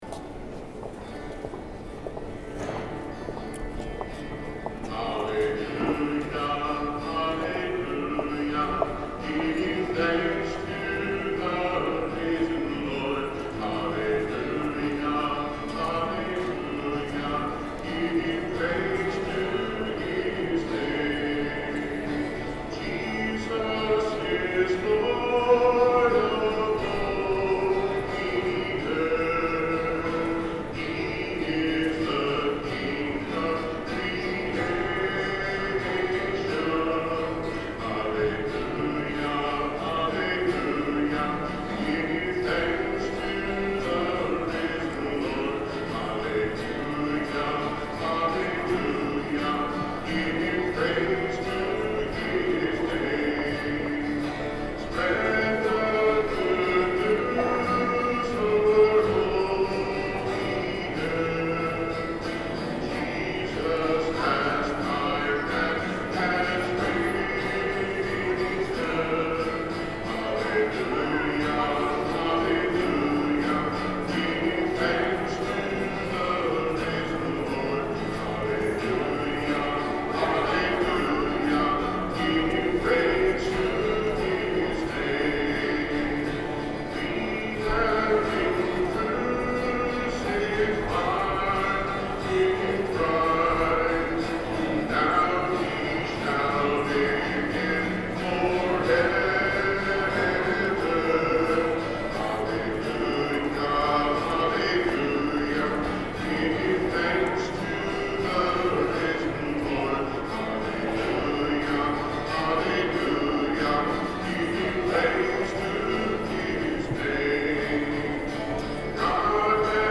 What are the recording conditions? Music from the 10:30 Mass Easter Sunday, March 31, 2013: